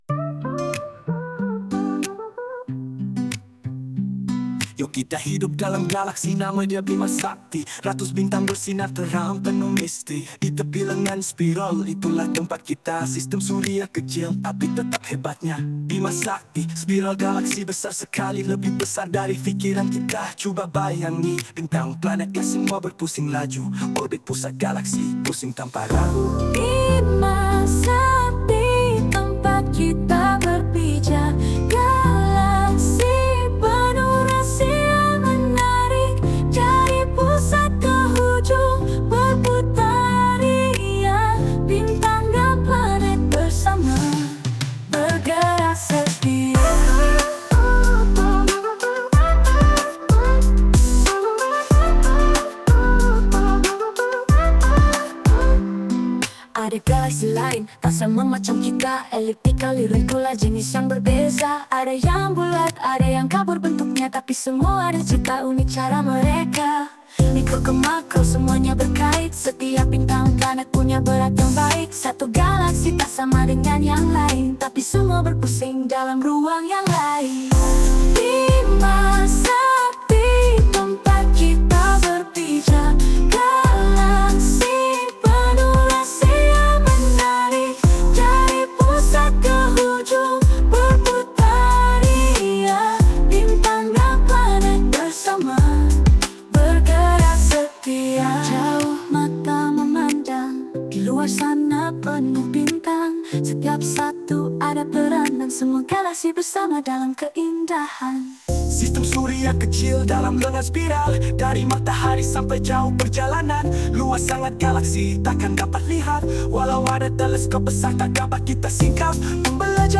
Berikut adalah lirik lagu bertemakan Unit 11: Galaksi dengan rentak RAP dan R&B yang merangkumi semua maklumat penting yang murid perlu pelajari.